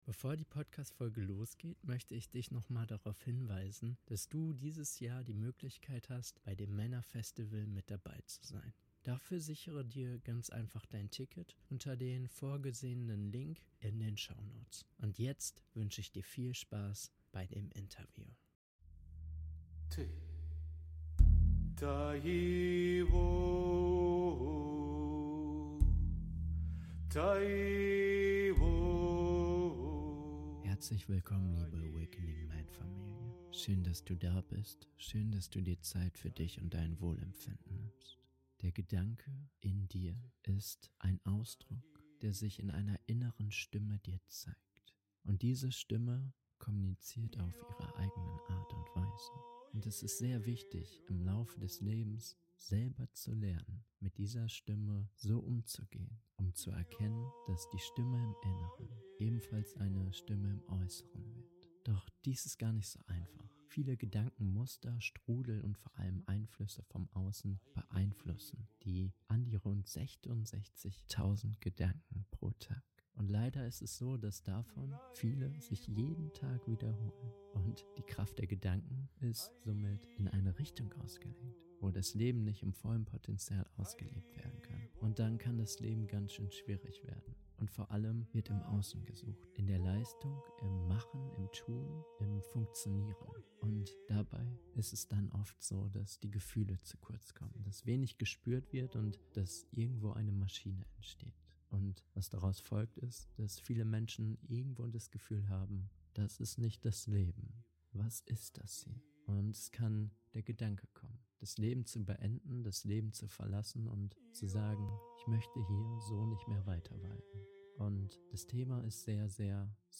AWAKENING MEN · E73 Suizidgedanken überwinden - Interview